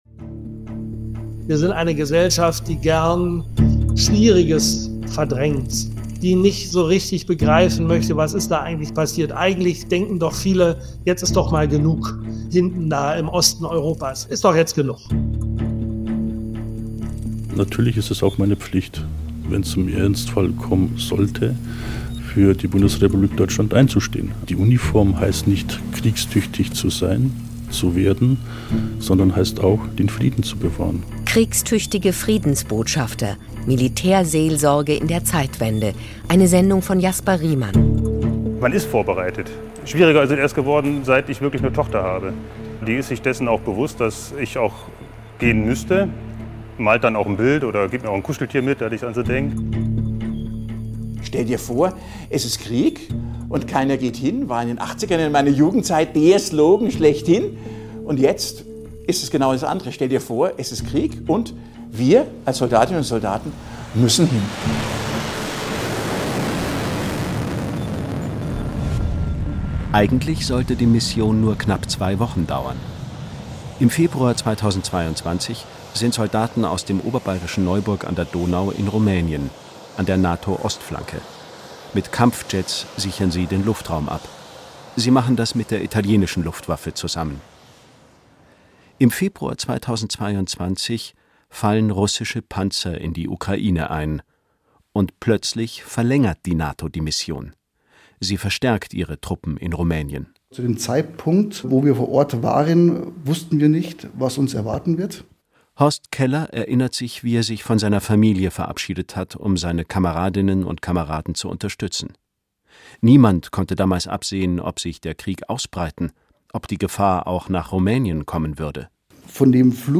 In SWR2 Glauben geht es um Auseinandersetzungen zu Glauben, Ethik und Sozialem. In hintergründigen Reportagen fragen wir nach, wie Menschen den Sinn ihres Lebens und ihren Kompass finden, wo sie integriert oder benachteiligt sind.